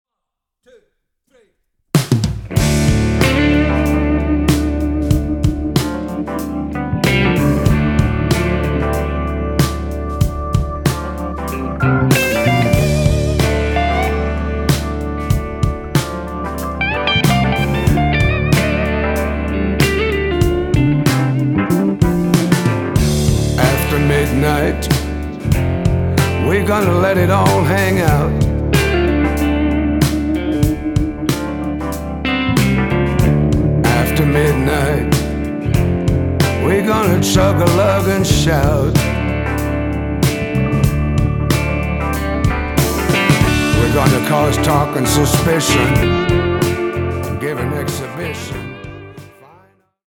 PIANO, HAMMOND, WURLITZER
RECORDED AT MALACO RECORDING STUDIOS, JACKSON, MISSISSIPPI
RECORDED AT FAME RECORDING STUDIOS, MUSCLE SHOALS, ALABAMA